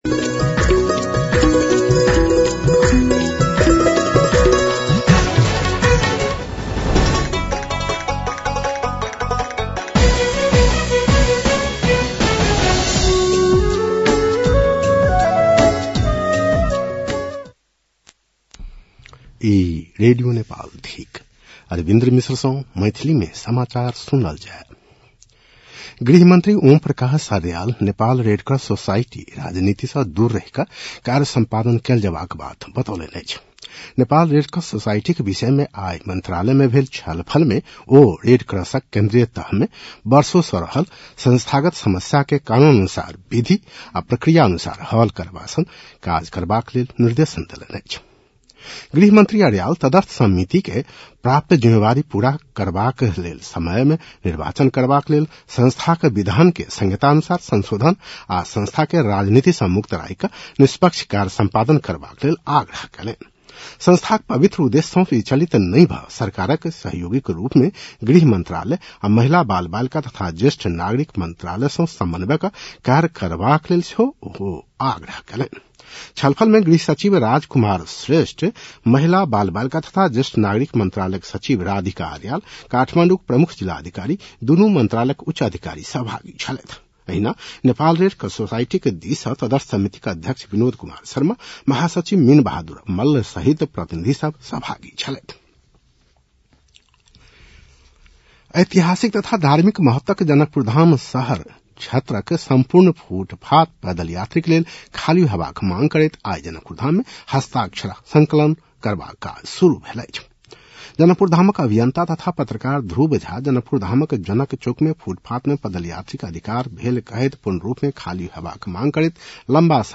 मैथिली भाषामा समाचार : १७ माघ , २०८२
6.-pm-maithali-news-1-9.mp3